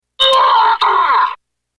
Chaque bouchon a une voix enregistrée qui lui est associée, cliquez sur le nom du bouchon pour l'écouter.